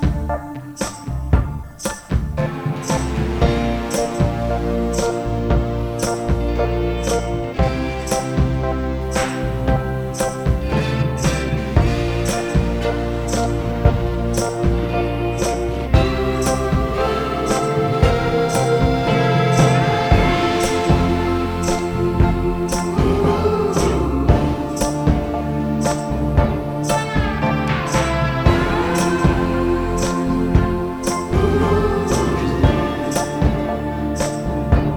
Жанр: Альтернатива
Easy Listening, Alternative